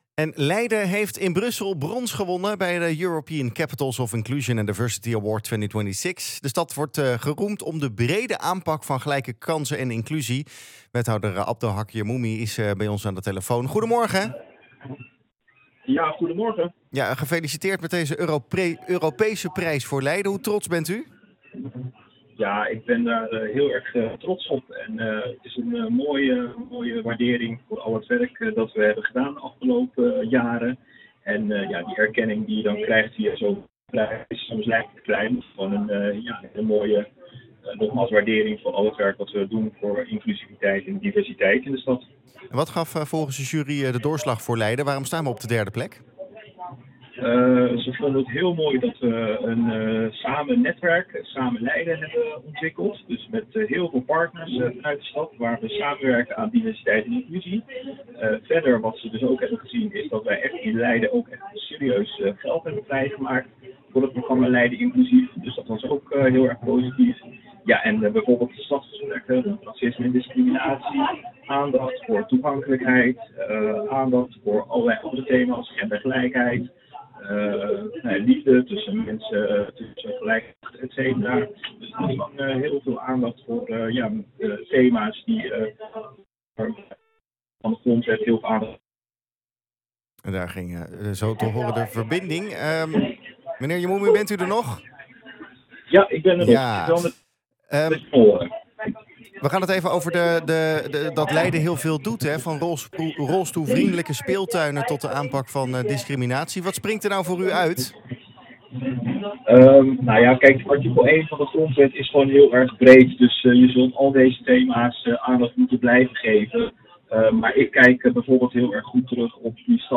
Wethouder Abdelhaq Jermoumi over het winnen van de award: